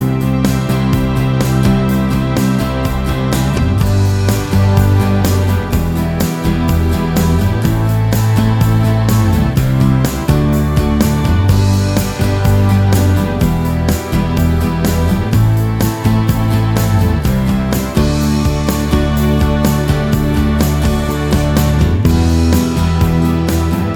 Minus Electric Guitar Indie / Alternative 3:55 Buy £1.50